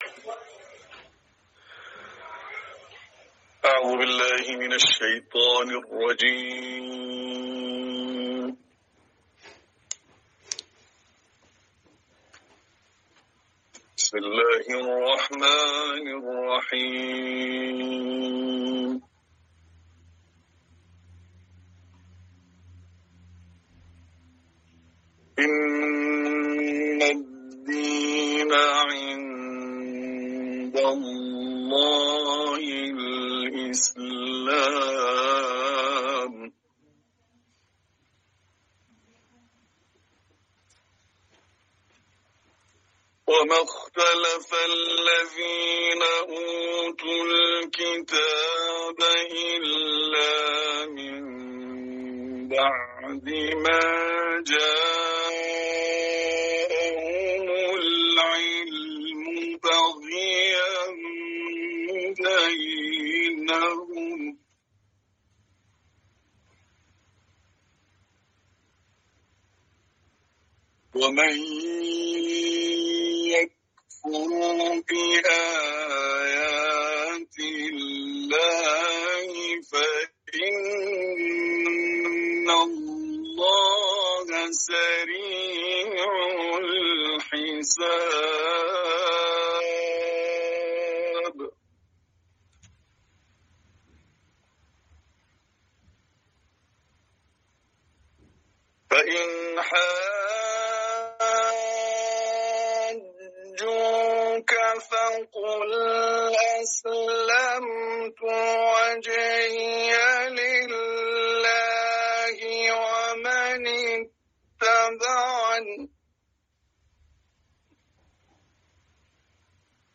تلاوت ، سوره آل عمران ، قاری